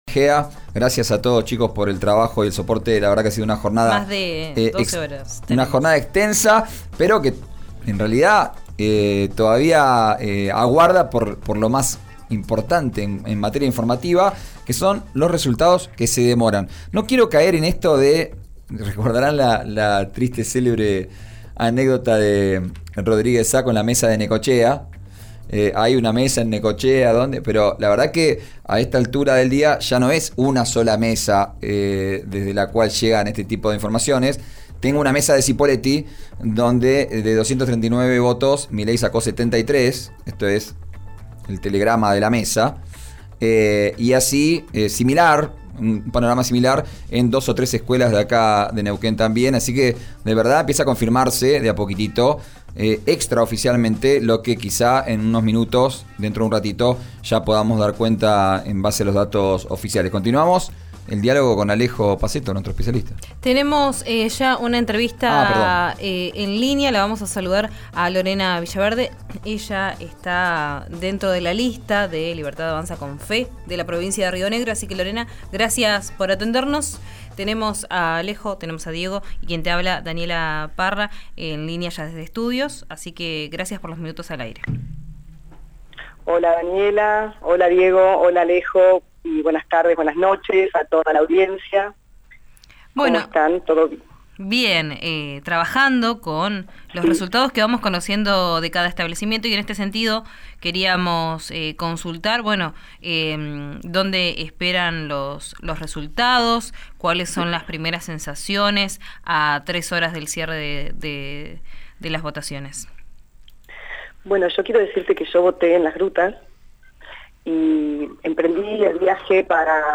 «Río Negro necesita que seamos valientes y tengamos coraje con el voto de la libertad», sostuvo Villaverde, a los micrófonos de RÍO NEGRO RADIO.
Escuchá a Lorena Villaverde en RÍO NEGRO RADIO: